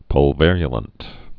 (pŭl-vĕryə-lənt, -vĕrə-)